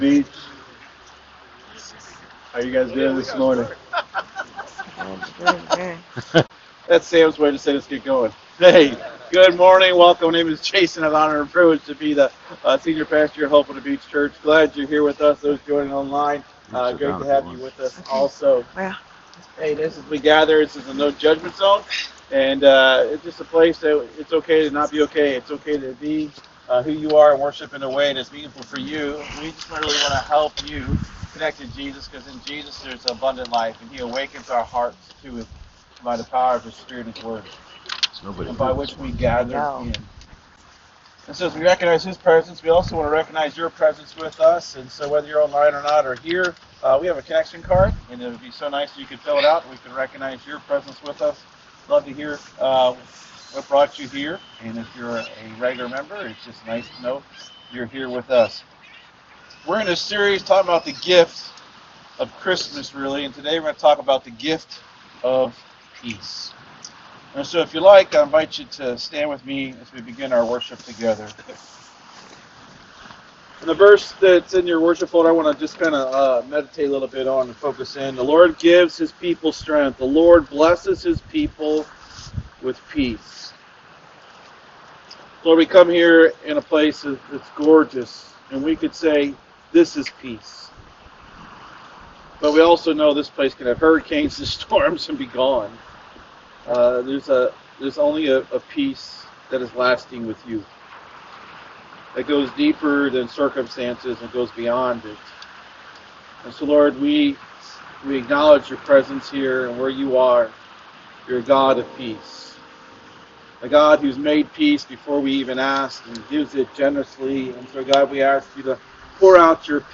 SERMON DESCRIPTION Delve into the message of the angels proclaiming peace on earth and good will to all.